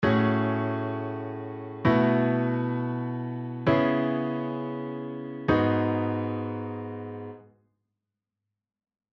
テンションコードの例
3声・4声より音が増えることで、複雑でオシャレな響きのコードになります。
↓の例は、某有名曲のコード進行で、A△7(13)⇒B7sus4⇒C#m7⇒G#m7（キー＝E）となっています。
A△7(13)⇒B7sus4⇒C#m7⇒G#m7